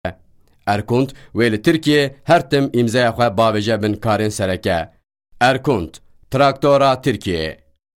Yabancı Seslendirme Kadrosu, yabancı sesler